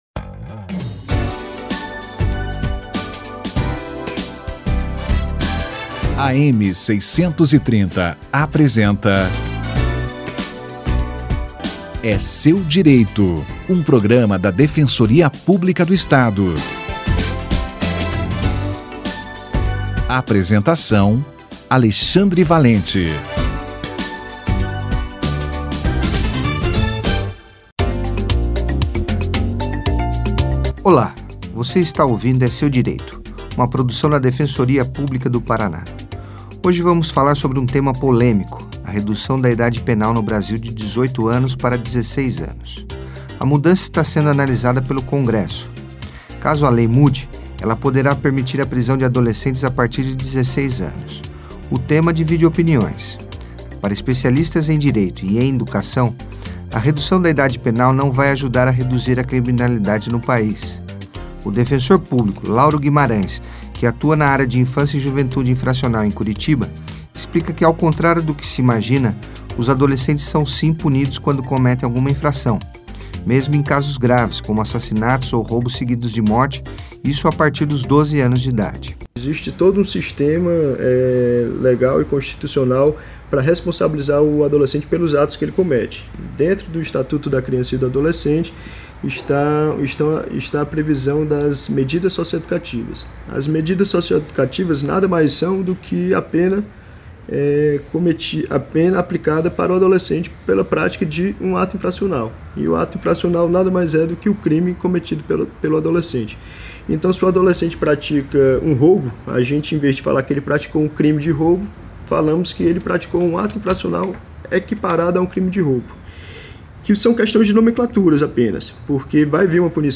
A redução da idade penal - Entrevista